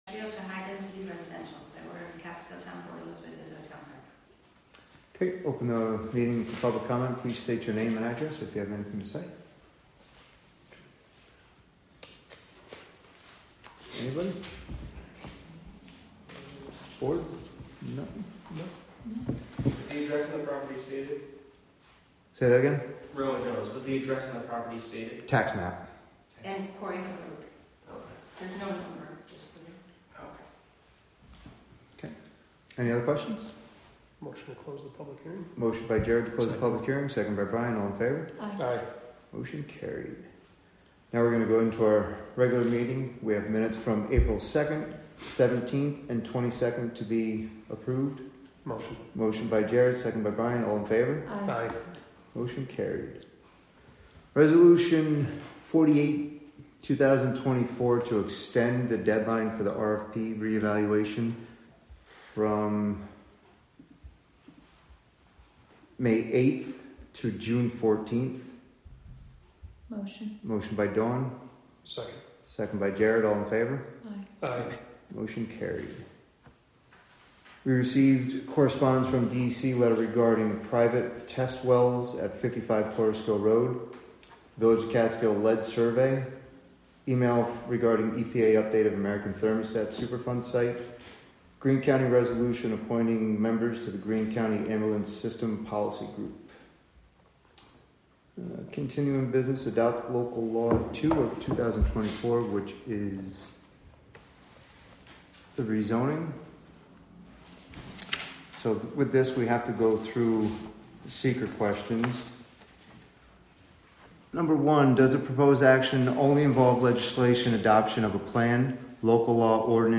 Live from the Town of Catskill: May 7, 2024 Catskill Town Board Meeting (Audio)